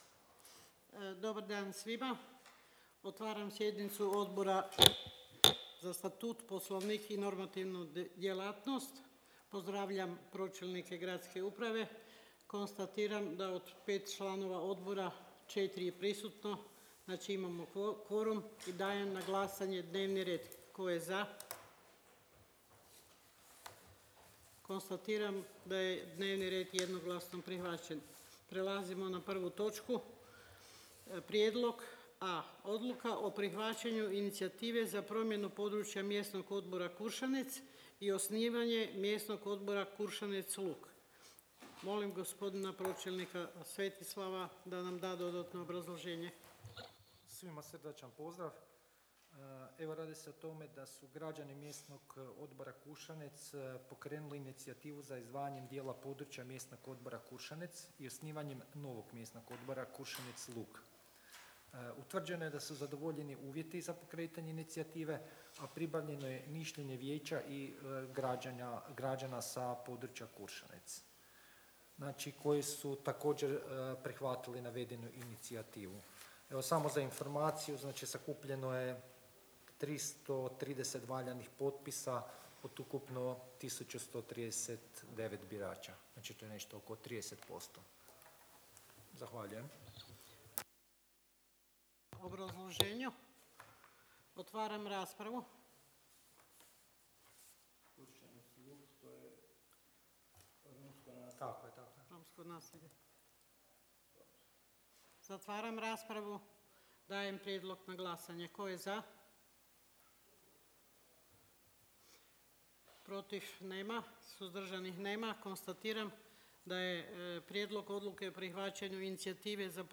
Obavještavam Vas da će se 19. sjednica Odbora za Statut, Poslovnik i normativnu djelatnost Gradskog vijeća Grada Čakovca održati dana 7. ožujka 2025. (petak) u 08:30 sati, u vijećnici Uprave Grada Čakovca.